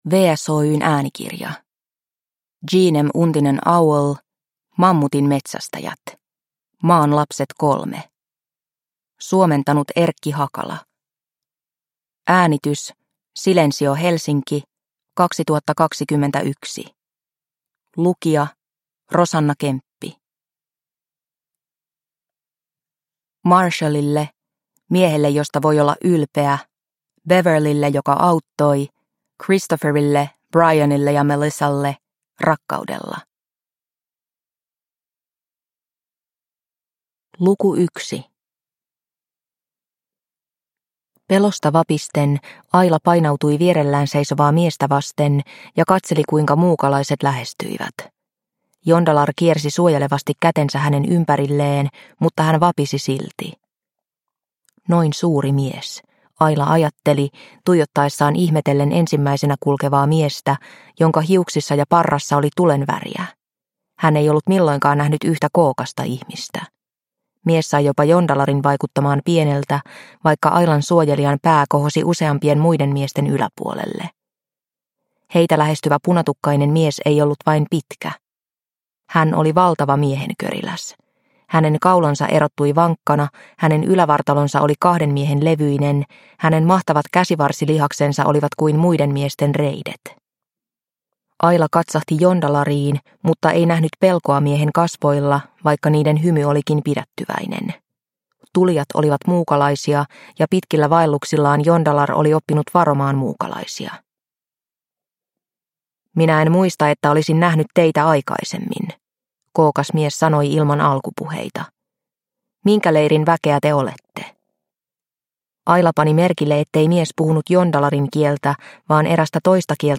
Mammutin metsästäjät – Ljudbok – Laddas ner